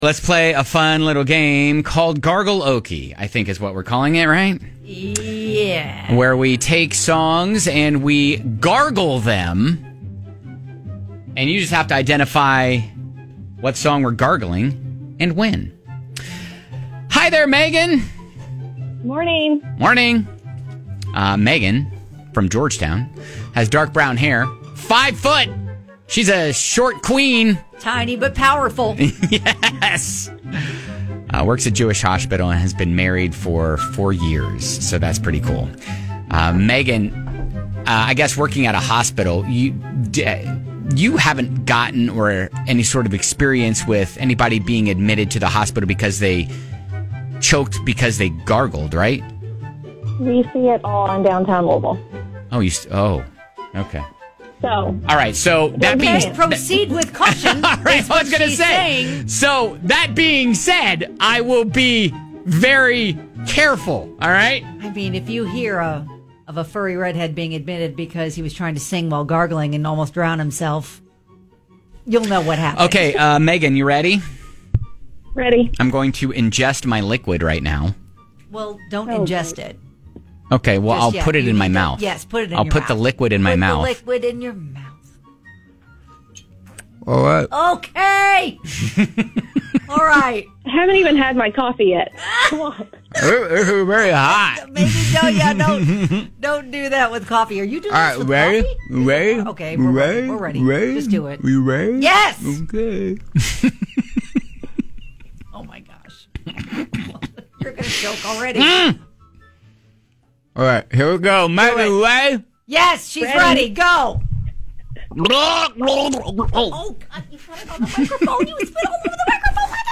We gargle songs - you guess.